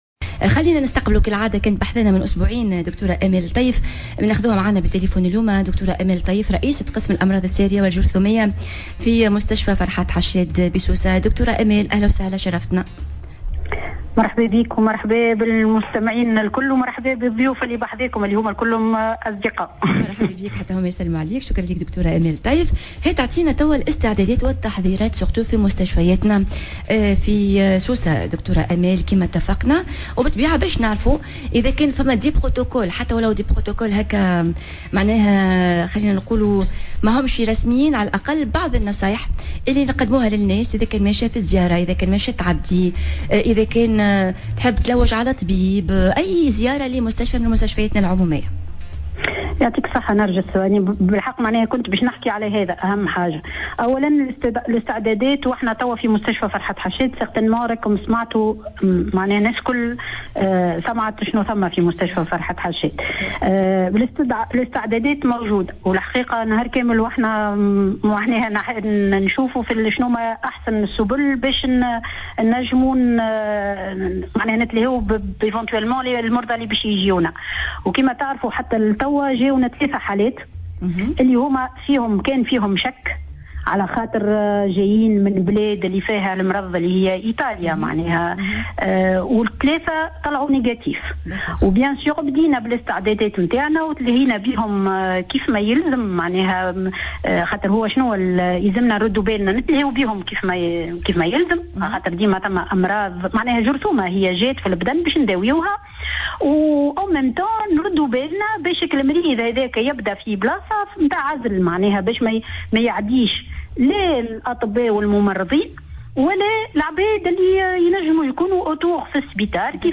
استضافتها في برنامج "ديالوق" على موجات الجوهرة أف أم